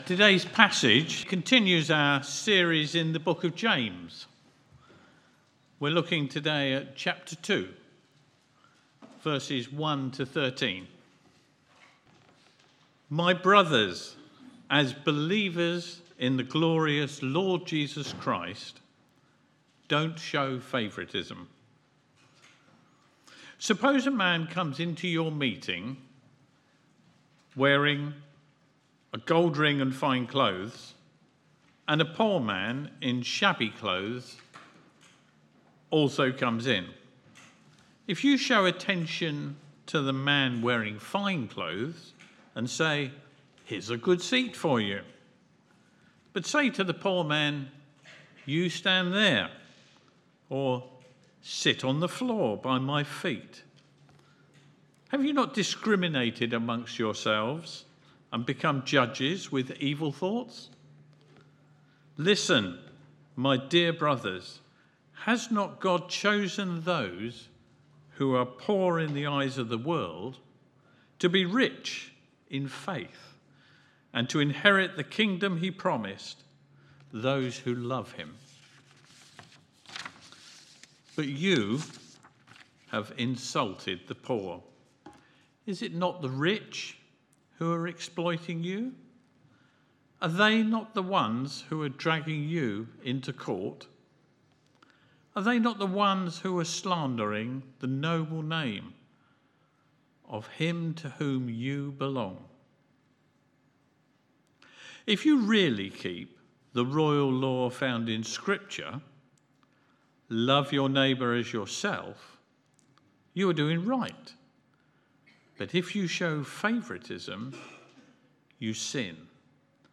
Media Library Media for Sunday Service on Sun 29th Jun 2025 10:00 Speaker
James 2:1-13 Series: James: Faith in Action - Real Faith, Real Life. Theme: Faith & Favouritism Sermon To find a past sermon use the search bar below You can search by date, sermon topic, sermon series (e.g. Book of the Bible series), bible passage or name of preacher (full or partial) .